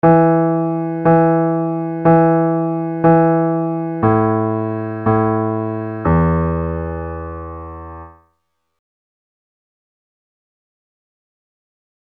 Key written in: E Major
Comments: Nice gentle reassuring tag.
Each recording below is single part only.